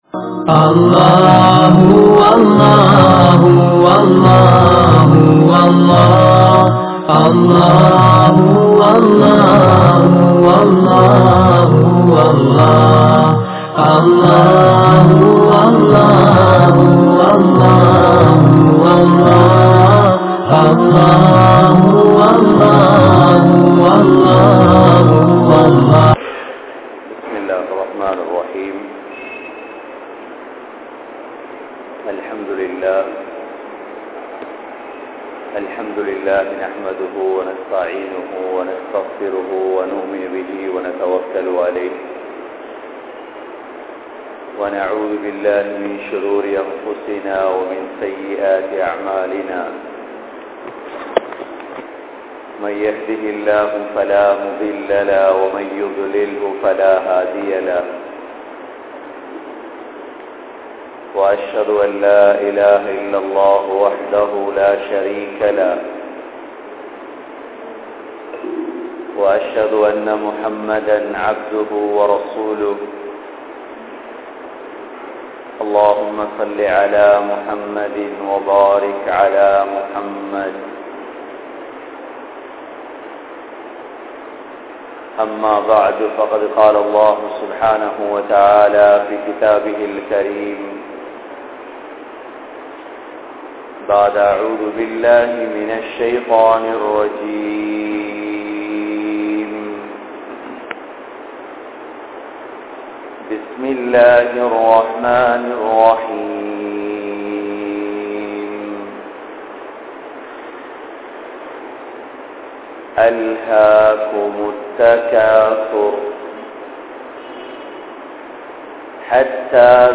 Uir Pirintha Vaalkai (உயிர் பிரிந்த வாழ்க்கை) | Audio Bayans | All Ceylon Muslim Youth Community | Addalaichenai